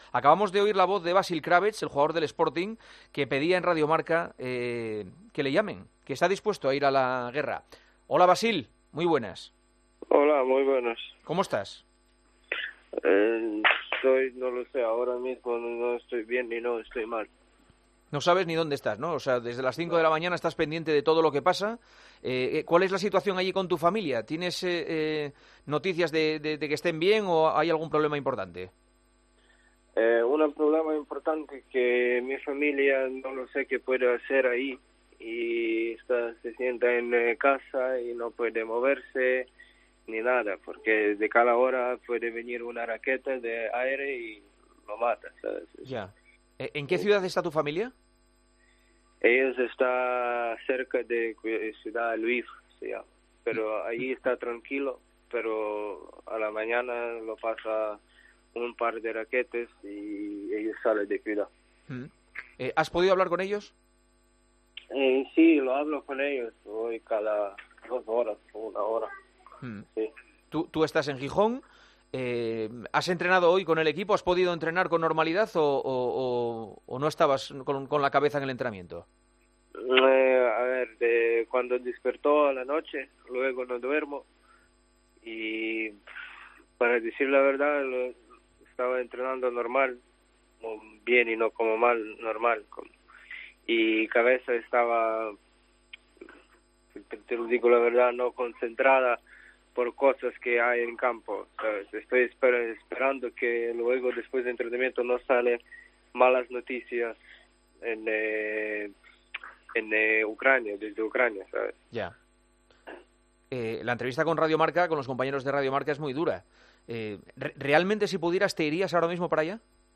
El jugador le ha contado a Juanma Castaño cómo se encuentra su familia en Ucrania y tiene claro que, a pesar de tener contrato con el Sporting, quiere ayudar a su país.